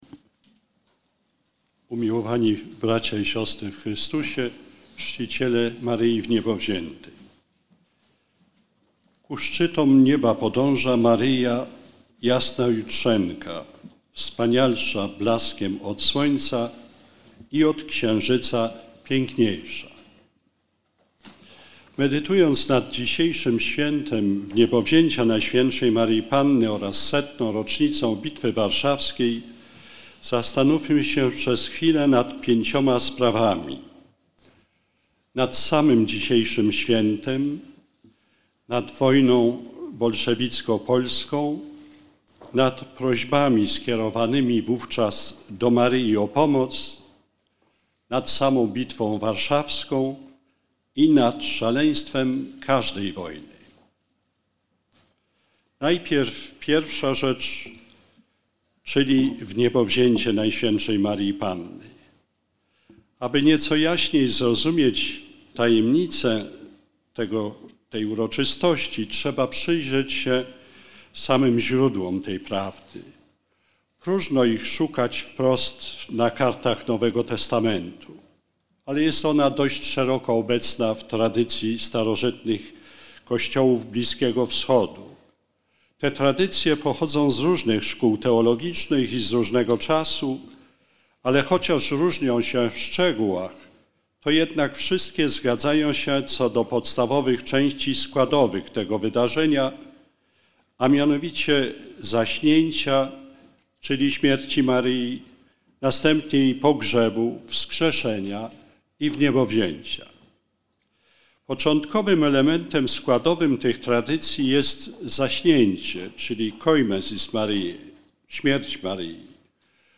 Uroczysta Msza Św. na Cmentarzu Bohaterów 1920 roku w Ossowie
Główne uroczystości otworzyła Msza Święta na terenie kaplicy przy Cmentarzu Bohaterów 1920 r.
cała-homilia-gądeckiego.mp3